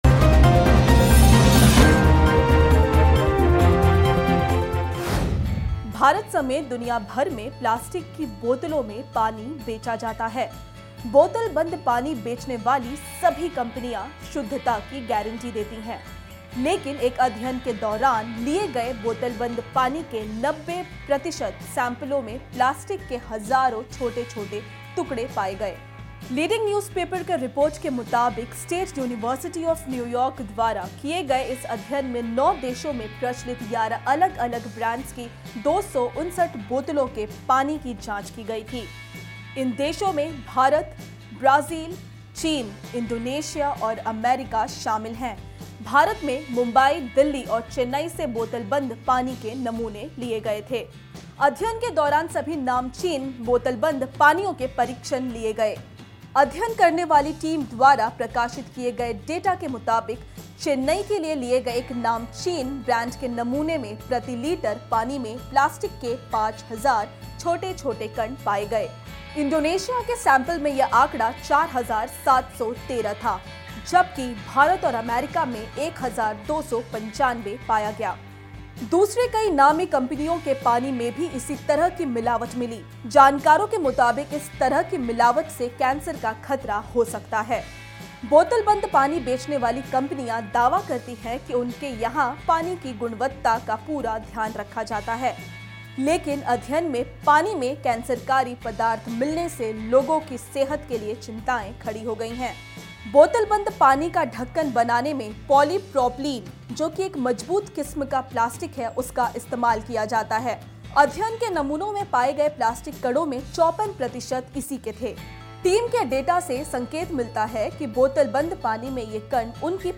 News Report / बोतलबंद पानी के 90 प्रतिशत सैमपल्स में मिला प्लास्टिक